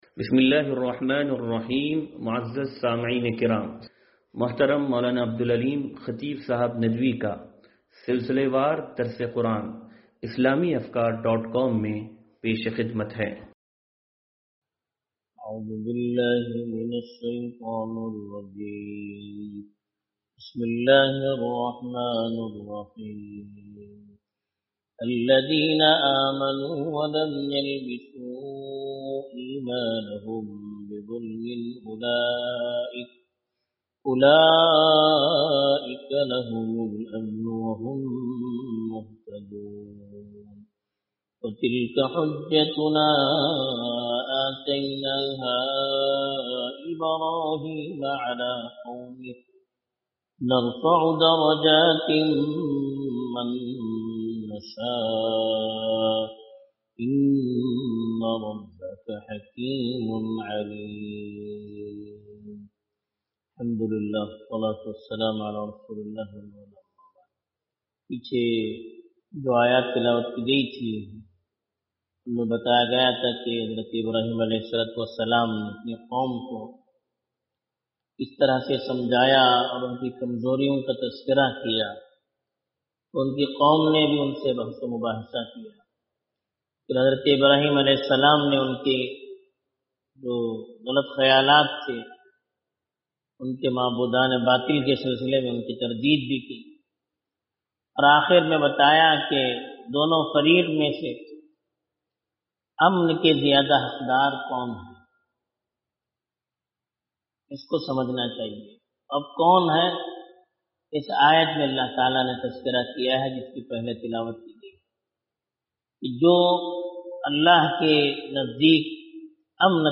درس قرآن نمبر 0543